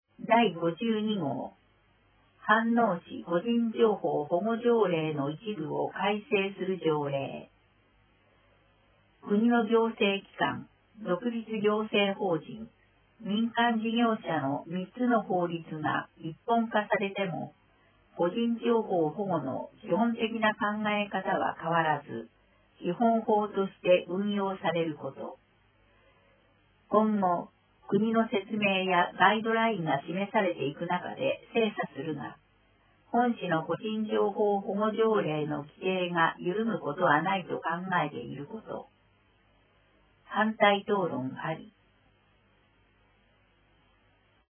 声の議会だより第161号(mp3)